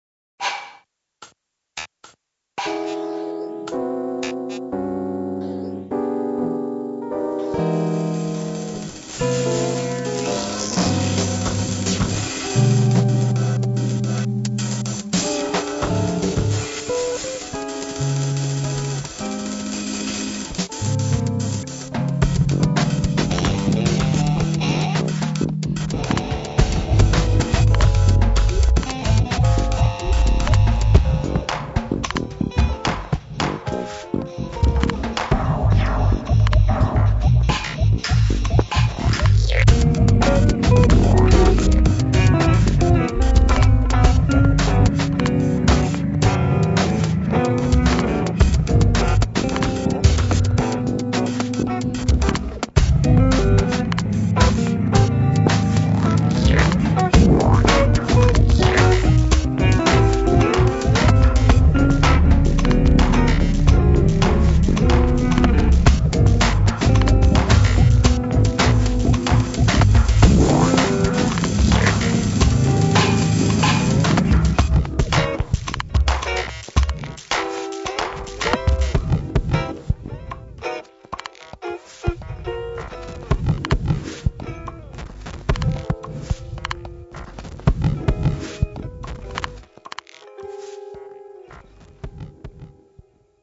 Style : Jazztronica
Pleasantly contrary jazztronica